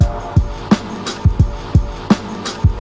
Drum Loop (All Caps).wav